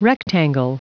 Prononciation du mot rectangle en anglais (fichier audio)
Prononciation du mot : rectangle